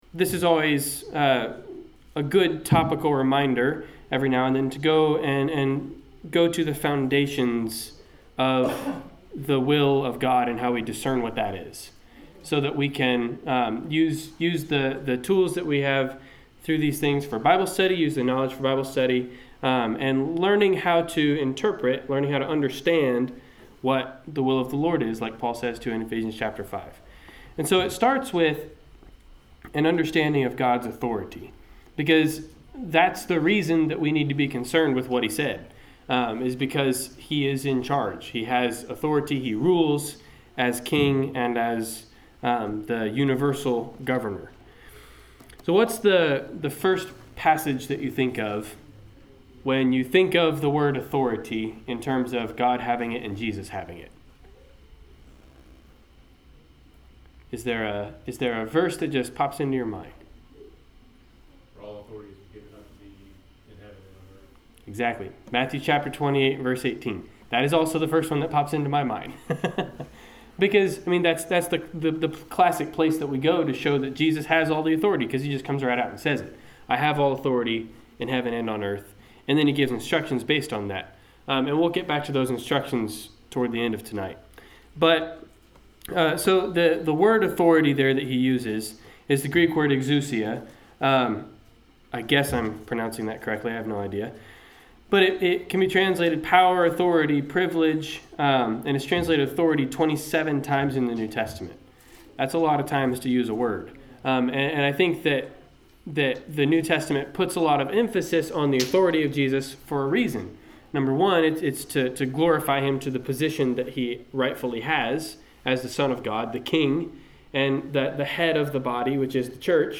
Service Type: Wednesday Night Class Topics: Biblical Authority
Authority-Wed-Night-Class-Edited.mp3